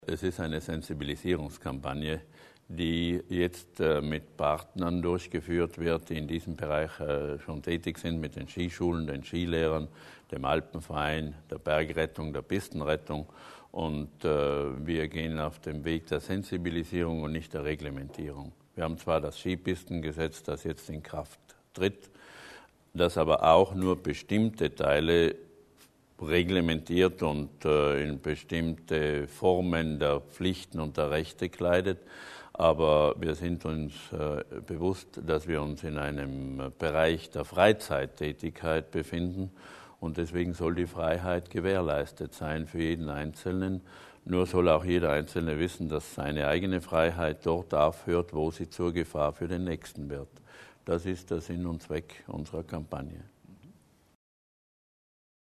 Landesrat Berger über die Ziele der Aufklärungs-Kampagne
LPA - Ein ganzes Bündel an Initiativen für mehr Sicherheit auf und rund um die Skipisten in Südtirol stellte Landesrat Hans Berger heute (30. November) im Rahmen einer Pressekonferenz im Palais Widmann vor.